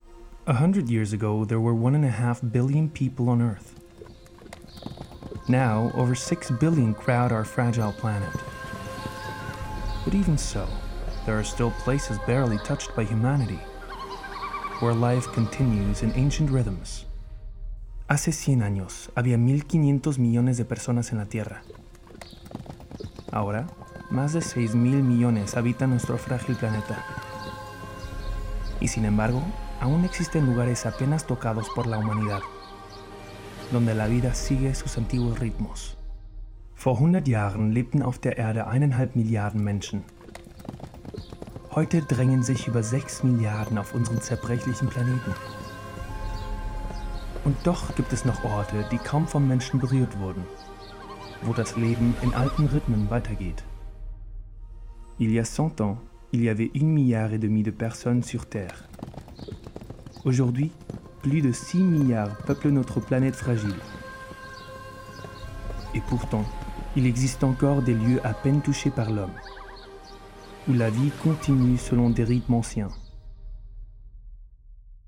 sehr variabel, hell, fein, zart, markant
Mittel minus (25-45)
Lip-Sync (Synchron), Overlay